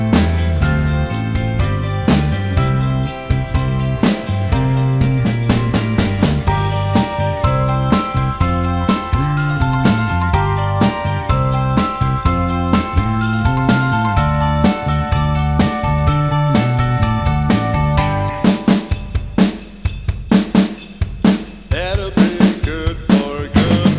Intro Cut Down Rock 3:44 Buy £1.50